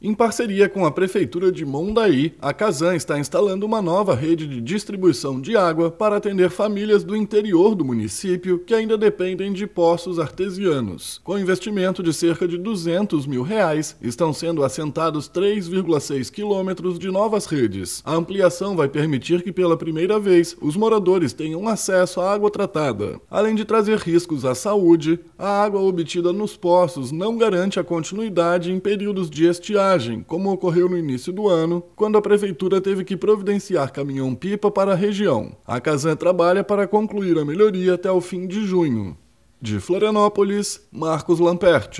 BOLETIM – Casan instala rede de água para comunidade do interior de Mondaí